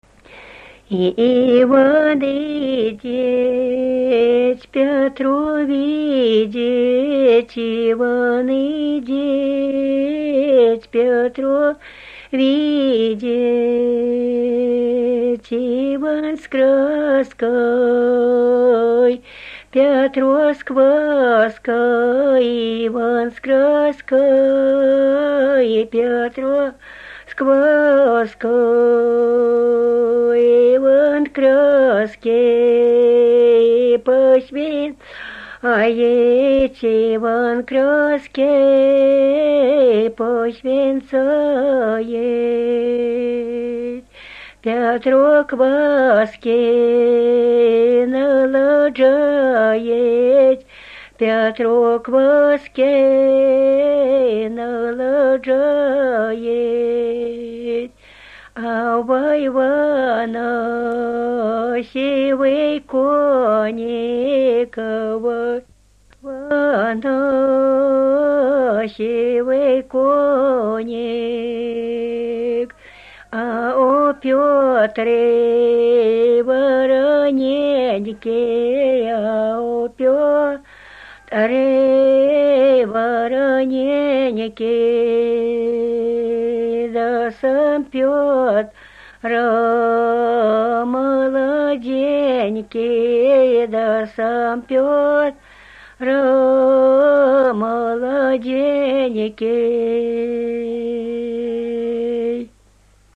Купальные обрядовые песни Невельского района